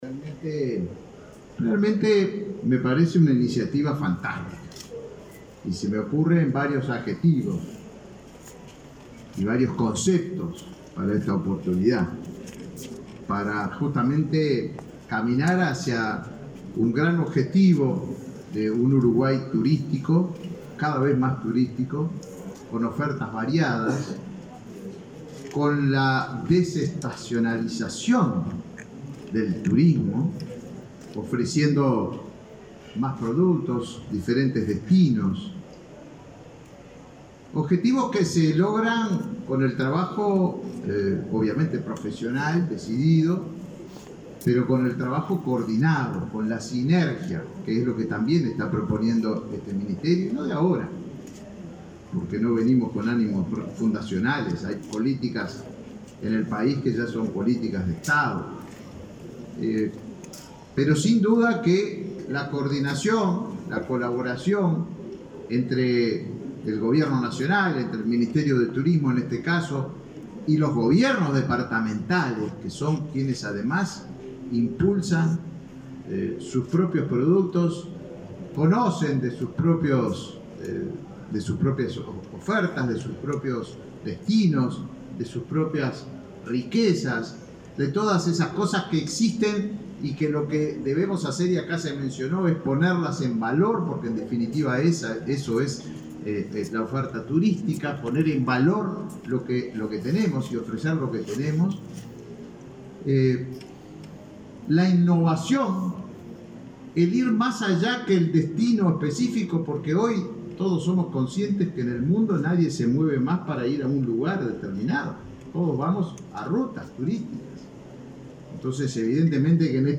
Palabras del ministro de Turismo, Tabaré Viera
Este jueves 16, en la Expo Prado, el ministro de Turismo, Tabaré Viera, participó del lanzamiento de una guía que muestra el acervo del patrimonio